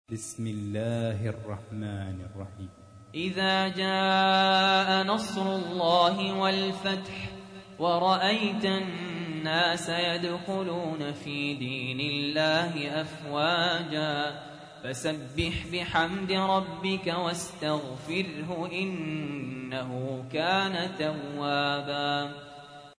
تحميل : 110. سورة النصر / القارئ سهل ياسين / القرآن الكريم / موقع يا حسين